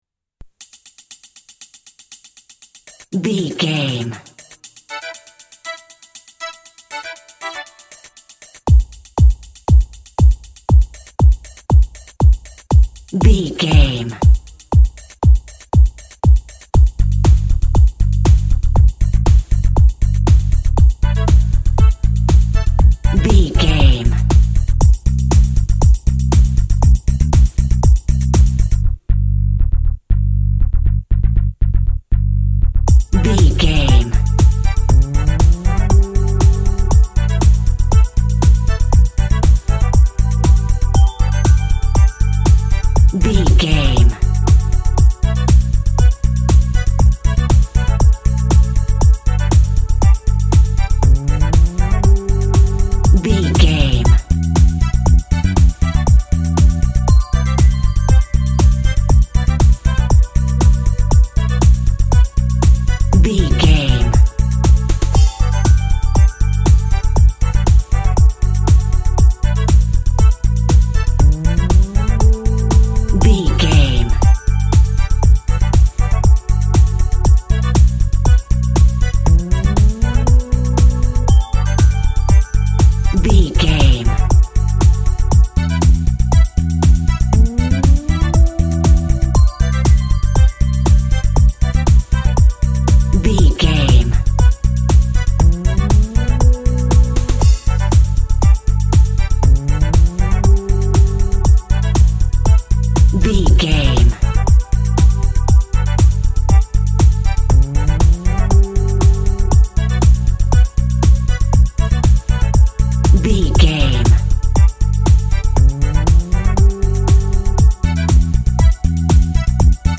Aeolian/Minor
groovy
uplifting
futuristic
energetic
cheerful/happy
synthesiser
drum machine
dance
techno
synth lead
synth bass
Synth Pads